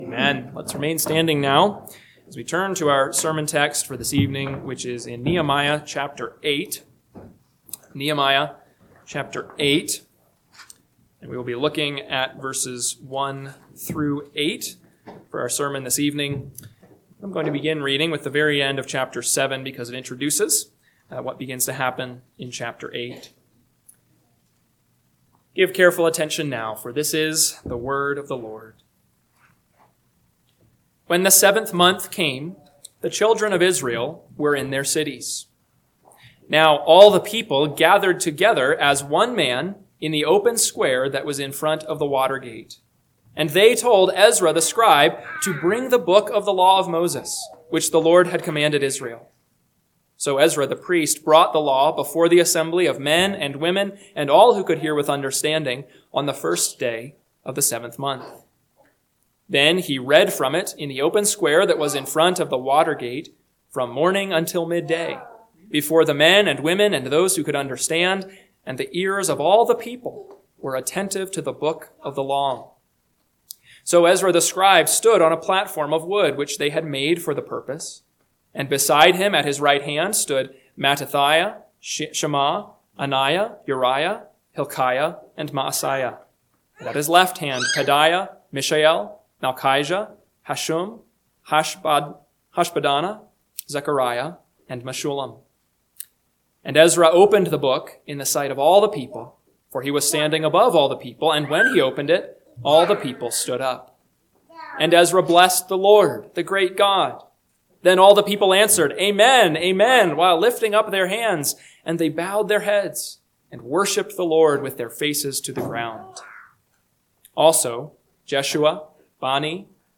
PM Sermon – 9/28/2025 – Nehemiah 8:1-8 – Northwoods Sermons